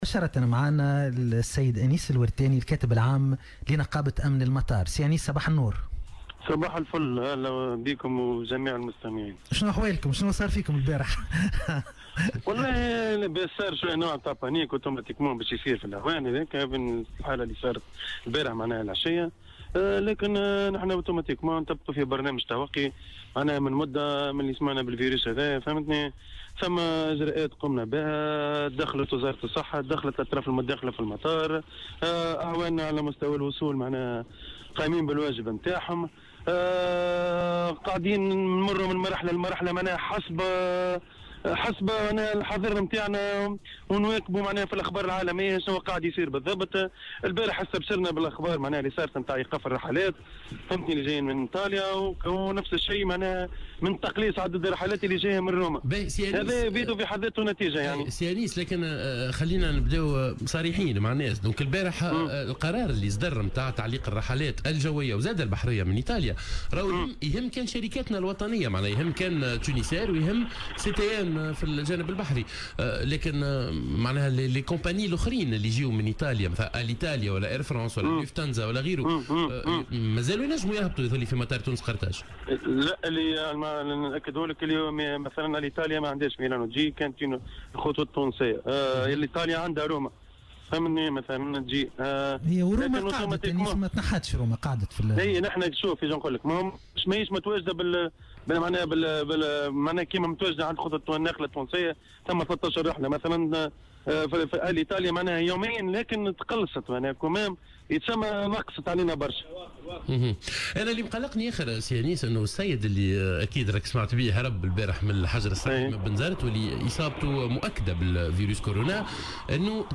وقال في مداخلة له اليوم على "الجوهرة أف أم": لم نستطع حتى إبلاغ العون الذي باشر هذه الحالة بالموضوع، علما وأنه كان يرتدي معدات الوقاية اللازمة..